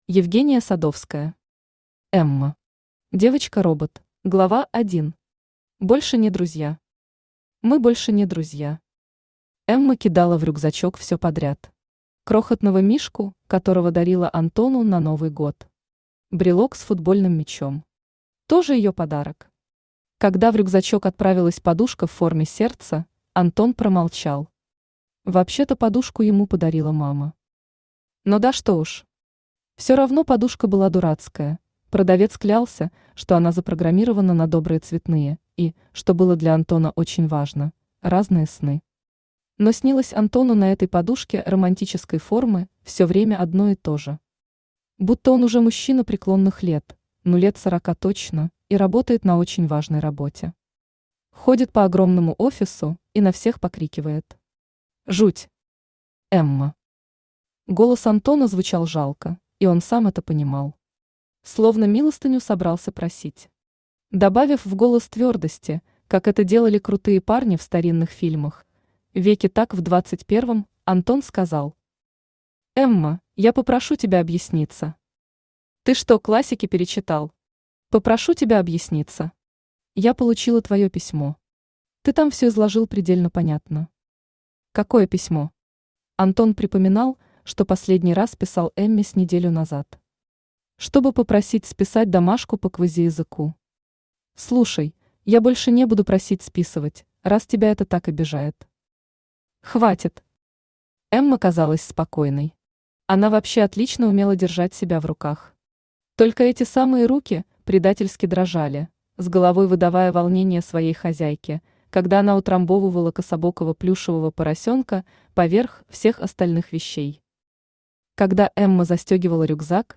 Аудиокнига Эмма. Девочка-робот | Библиотека аудиокниг
Aудиокнига Эмма. Девочка-робот Автор Евгения Олеговна Садовская Читает аудиокнигу Авточтец ЛитРес.